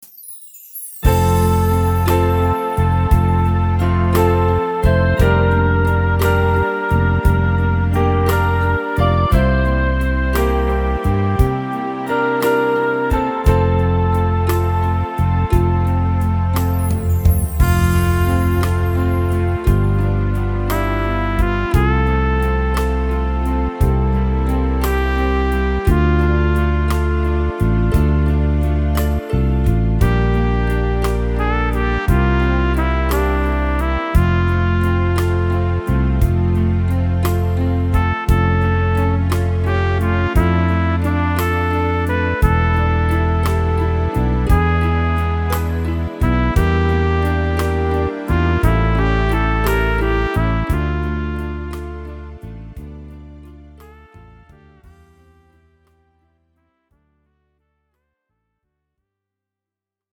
Tempo: 58 / Tonart: F – Dur